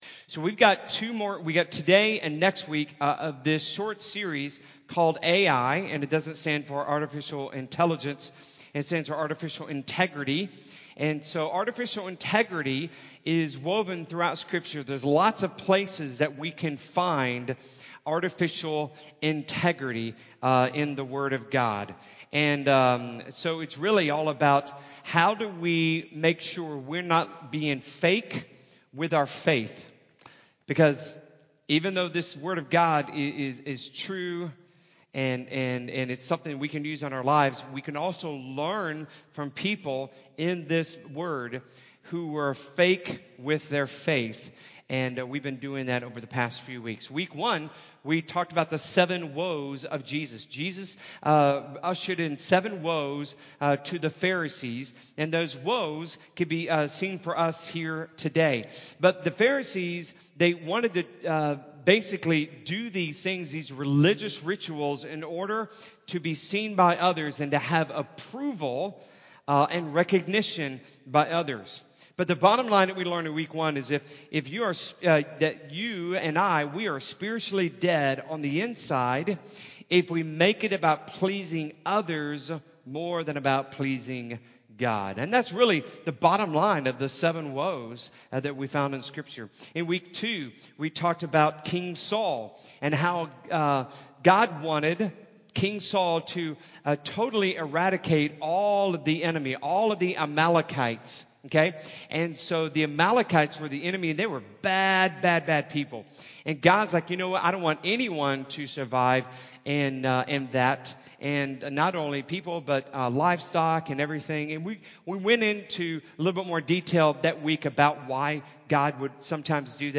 One-Thing-You-Lack-Sermon-Audio-CD.mp3